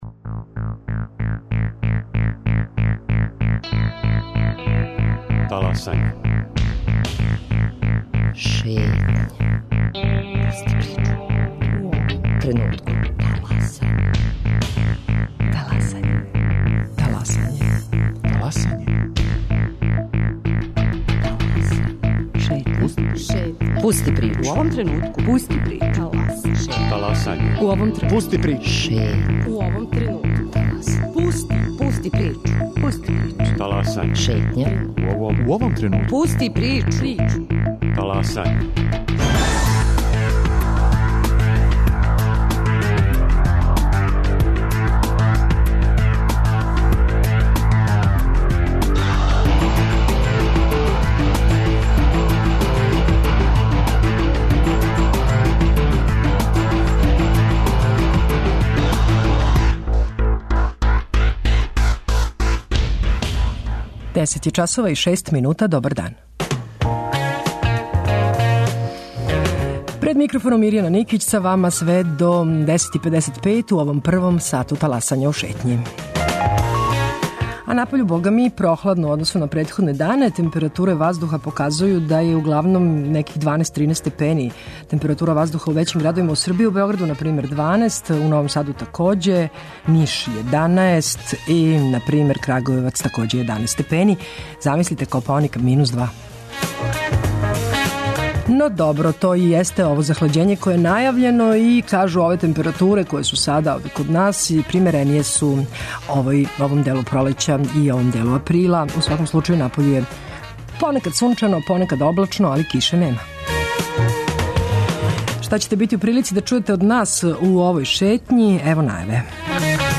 Чућете и причу забележену у Мостару, који је, више од две деценије од завршетка рата на простору бивше Југославије, и даље подељен град, иако је формално уједињен пре неколико година.